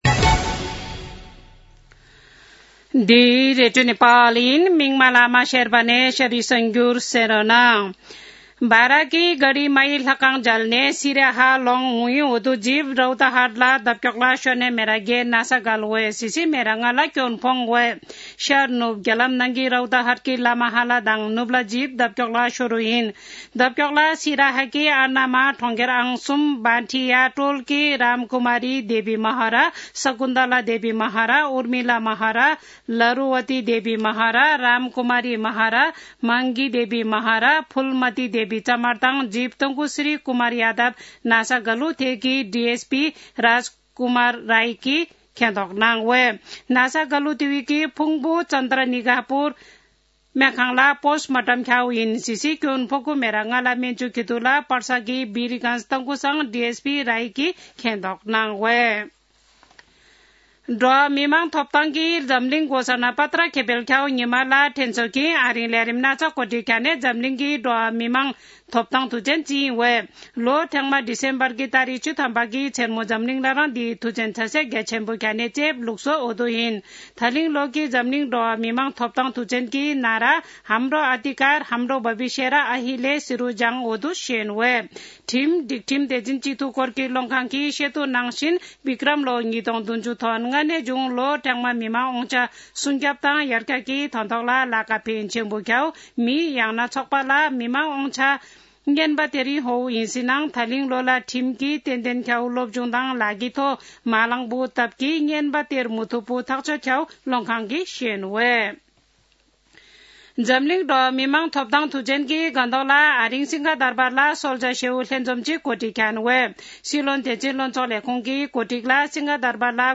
शेर्पा भाषाको समाचार : २६ मंसिर , २०८१
4-pm-Sherpa-news-1-3.mp3